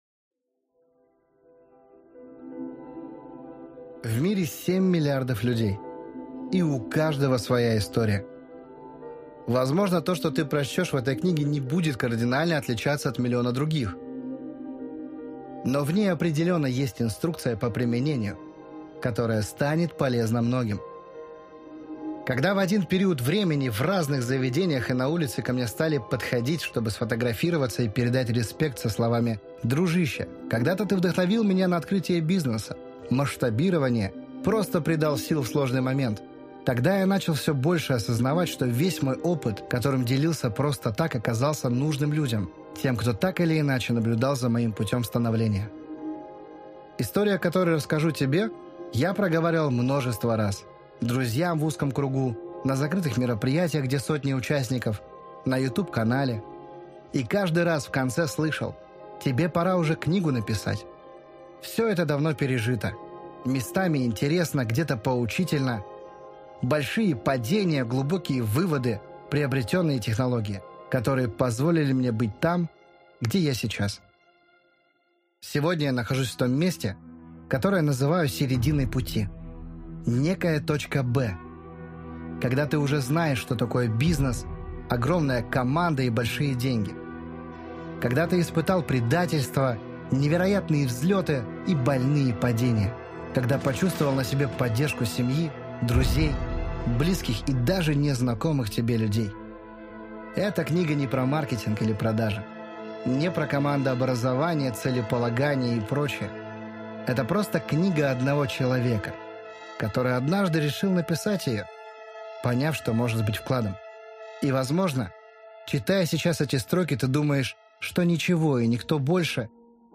Аудиокнига История одного ЧЕЛОВЕКА | Библиотека аудиокниг
Прослушать и бесплатно скачать фрагмент аудиокниги